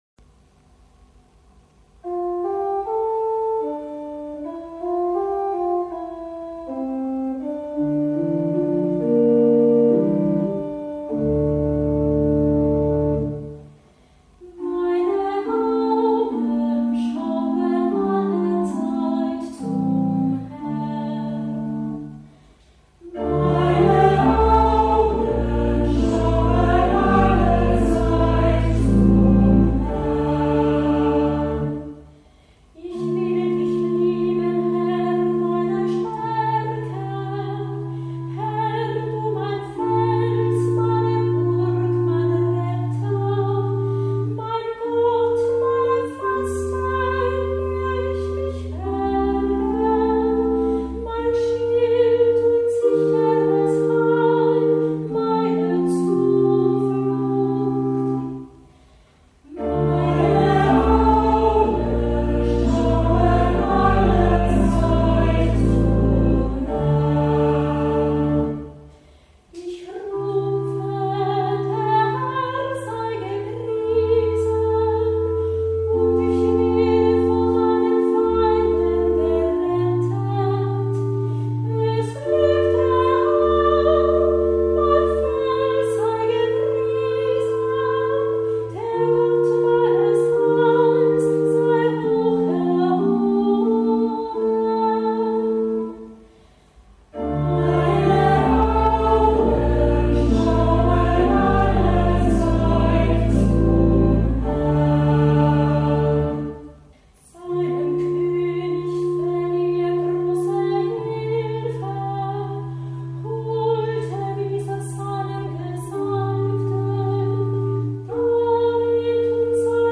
Hörbeispiele aus Kantorenbüchern und eine Auswahl aus dem Gurker Psalter
Ein- und mehrstimmige Antwortpsalmen